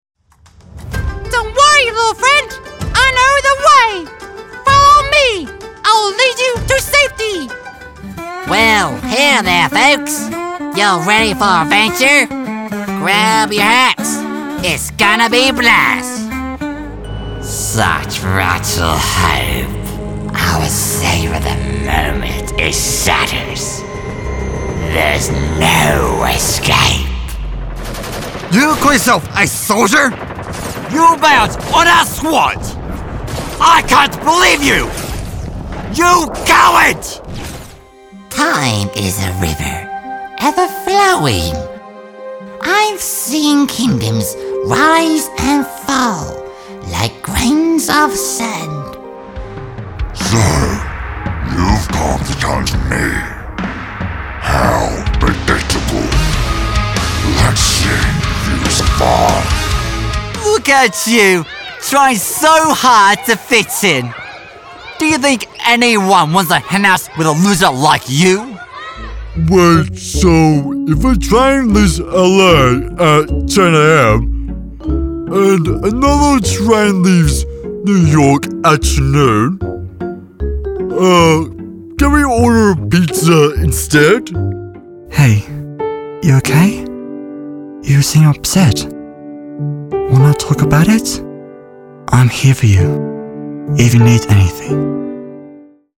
Cartoon Voice Over, Animation Voice-overs | Character Voice Over Talent
0701Animation_Demo_Reel.mp3